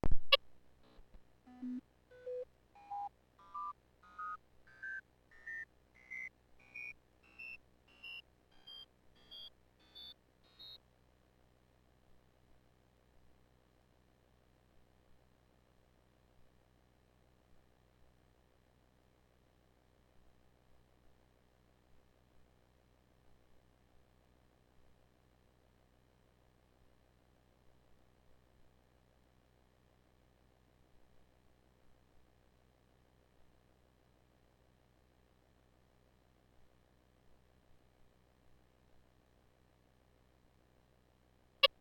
In this chunk I change 1 bit from magic sequence for each 10 frames.